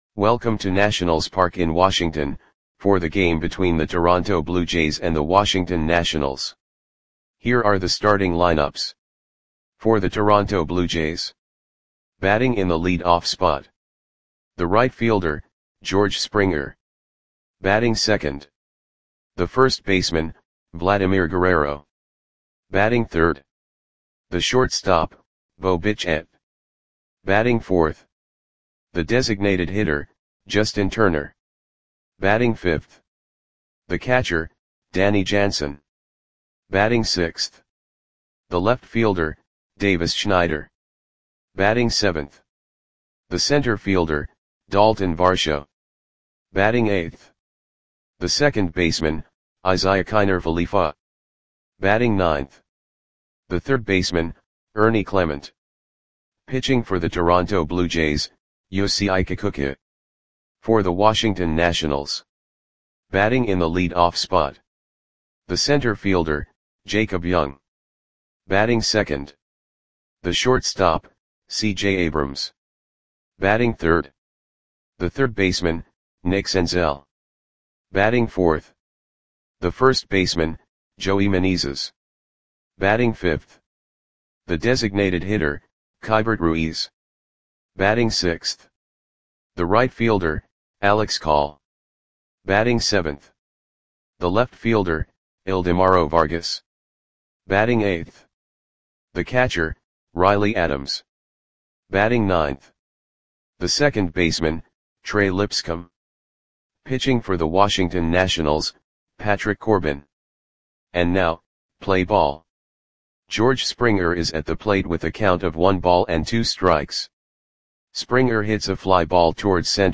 Audio Play-by-Play for Washington Nationals on May 3, 2024
Click the button below to listen to the audio play-by-play.